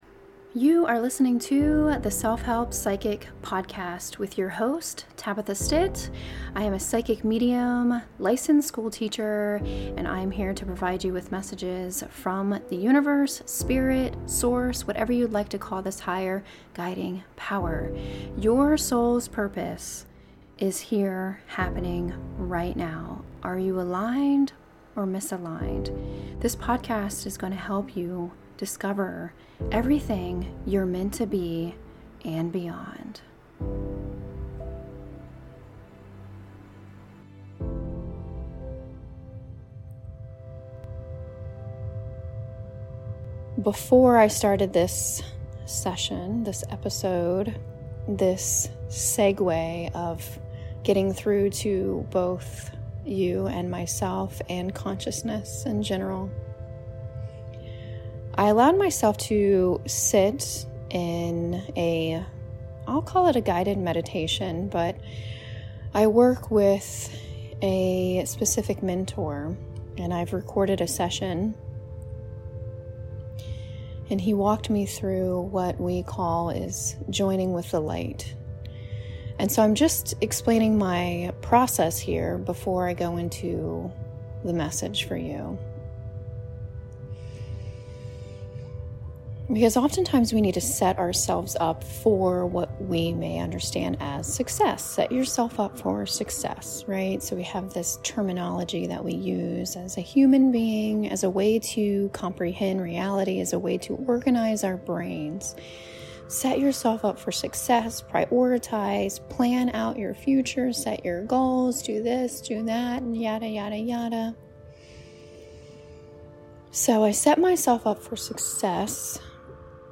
Be Still and Know That I Am God: A Simple Meditation